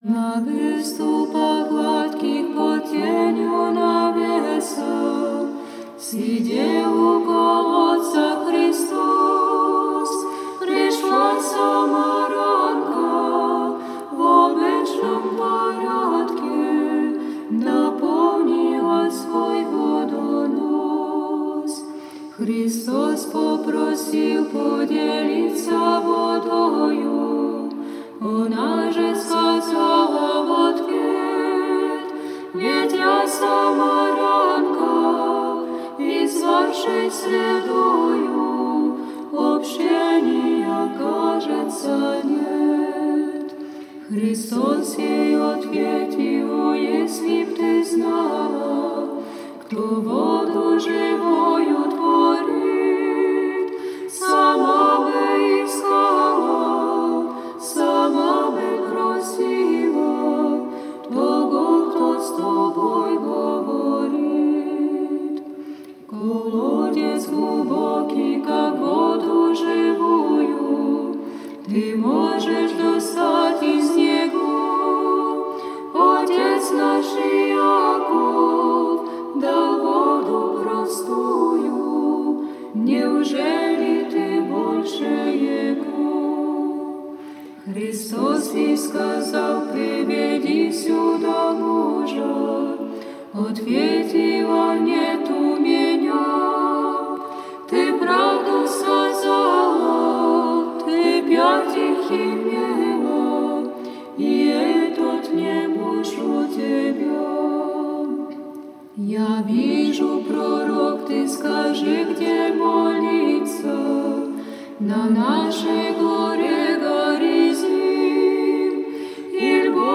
Spievajú sestry z poľského pravoslávneho monastiera Pokrova Božej matky v Turkovicach